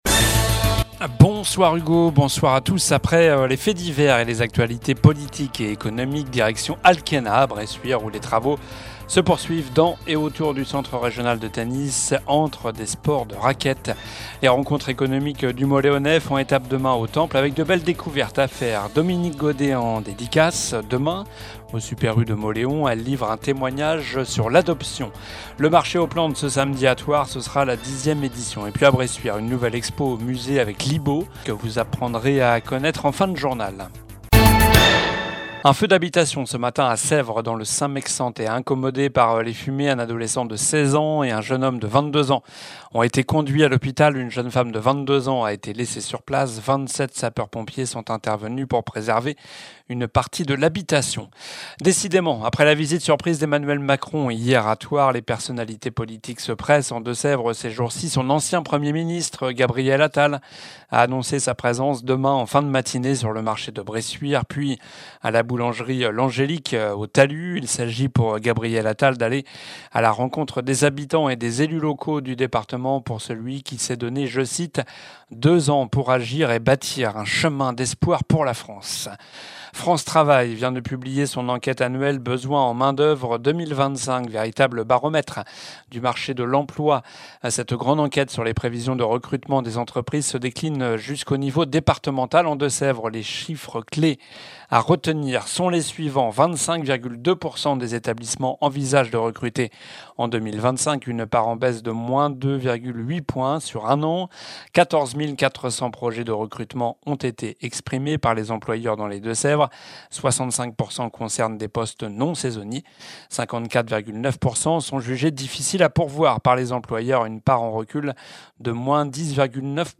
Journal du vendredi 11 avril (soir)